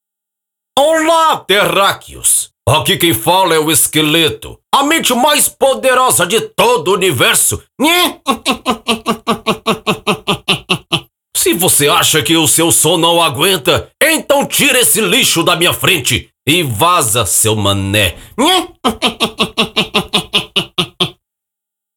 Voz ESQUELETO HE-MAN
Caricata